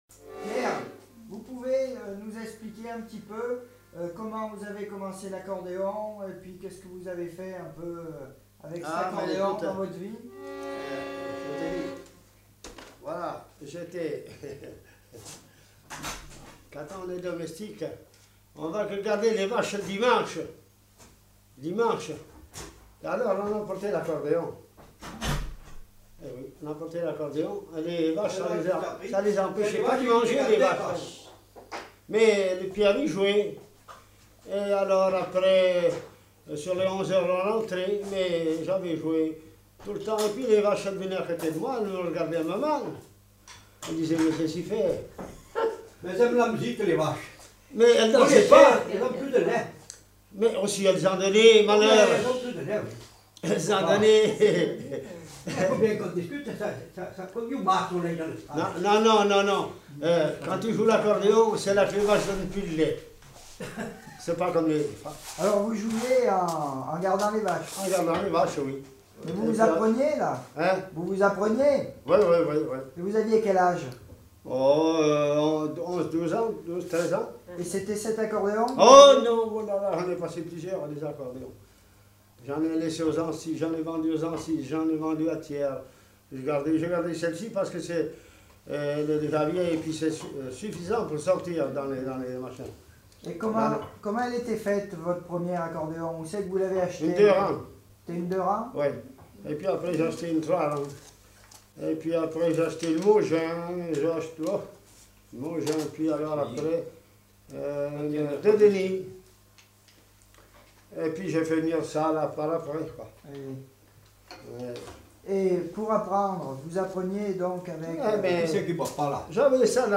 Lieu : Peschadoires
Genre : récit de vie